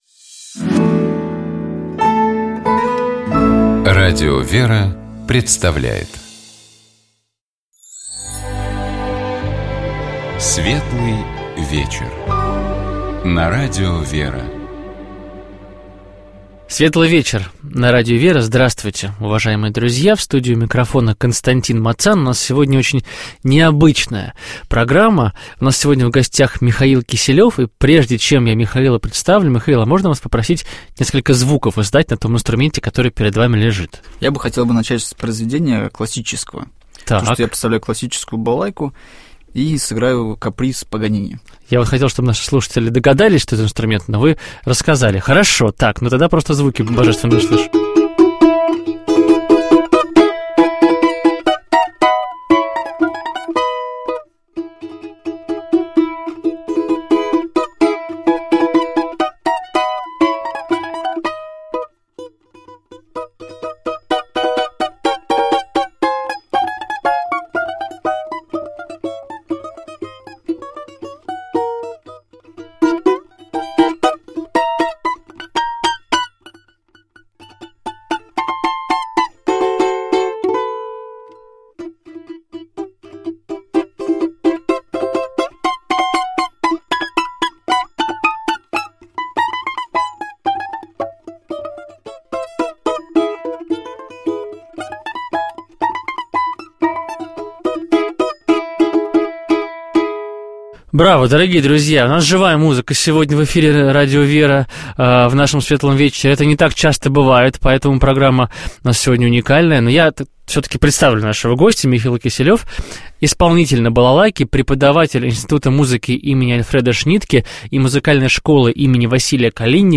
Браво, дорогие друзья, у нас живая музыка сегодня в эфире Радио ВЕРА в нашем «Светлом вечере», это не так часто бывает, поэтому программа у нас сегодня уникальная.